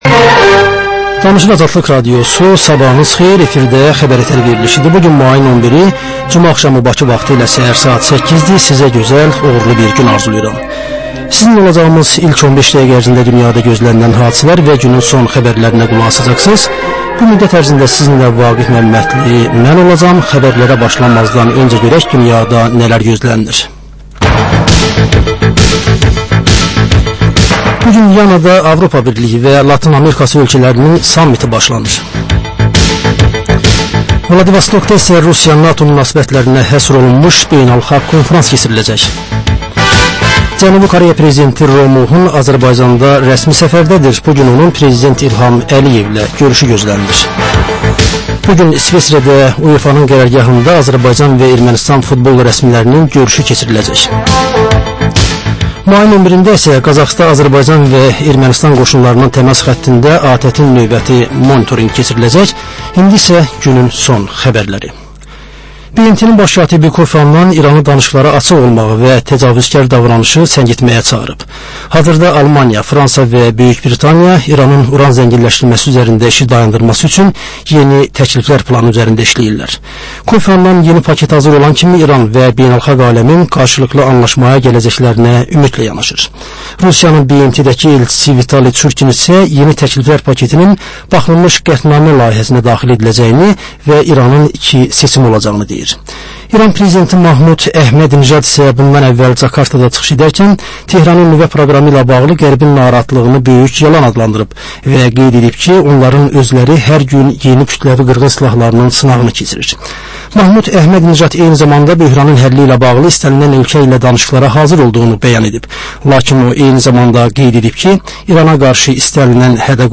Xəbərlər
Xəbər, reportaj, müsahibə.